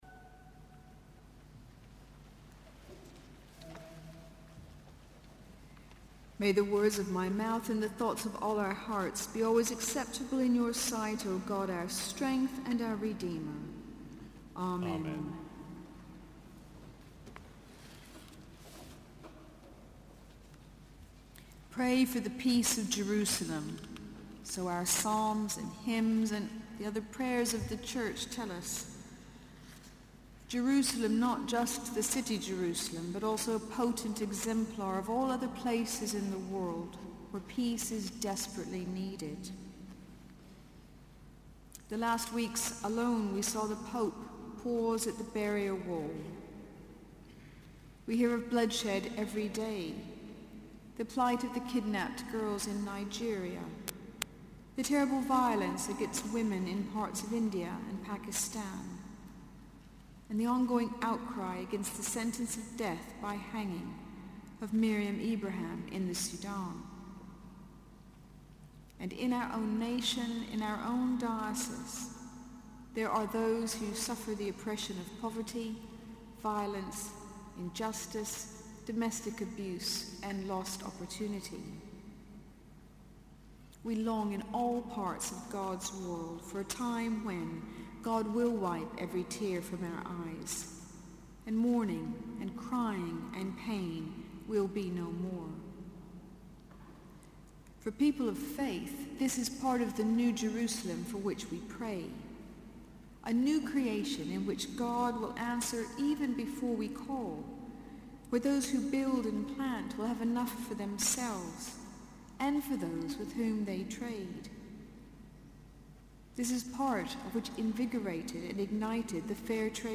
Sermon: Mattins - 1 June 2014